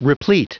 Prononciation du mot replete en anglais (fichier audio)